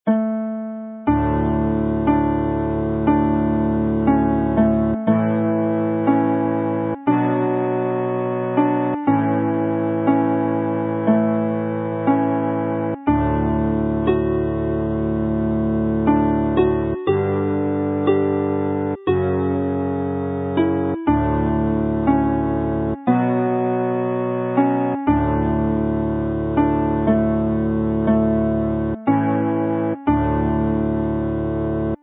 slow, with harmony